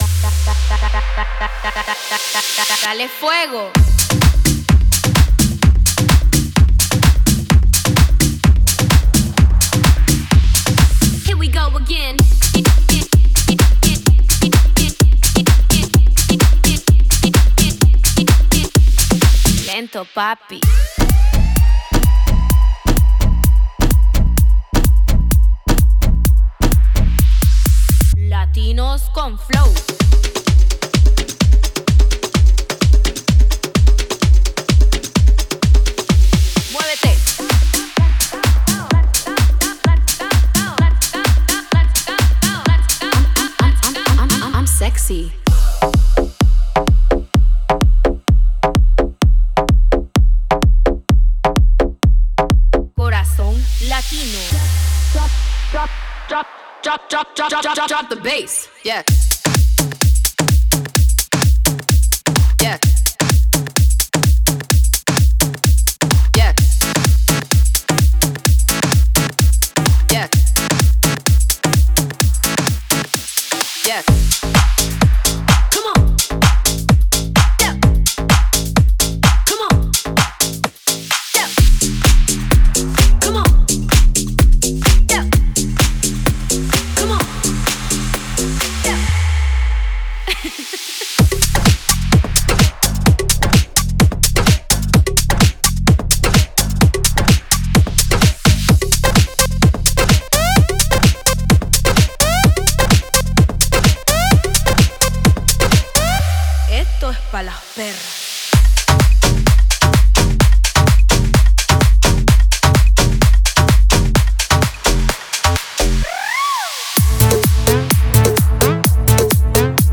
Genre:Tech House
各サウンドは実際の楽器を使用し、パーカッションやグルーヴの実験を重ねた結果として形作られています。
ループは127～136 BPMで演奏され、最適なテンポで使用できます。
デモサウンドはコチラ↓
80 Latin Vocal Phrases
66 English Vocal Phrases